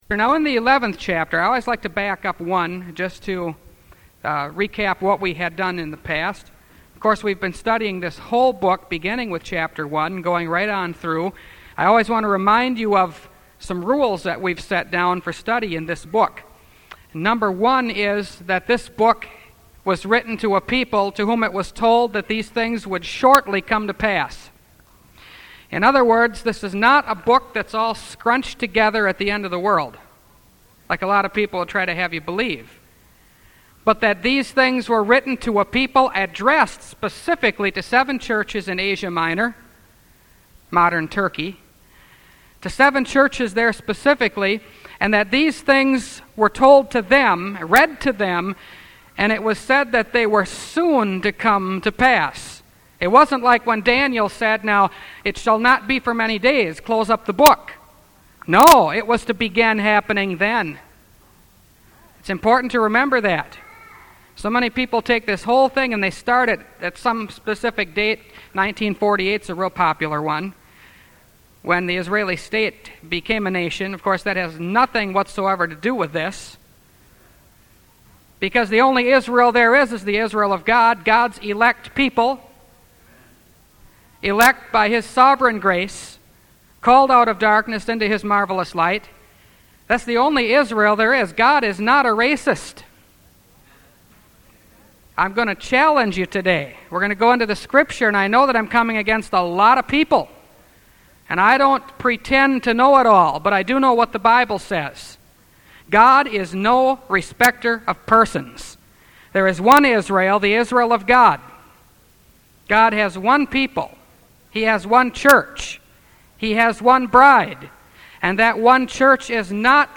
Revelation Series – Part 16 – Last Trumpet Ministries – Truth Tabernacle – Sermon Library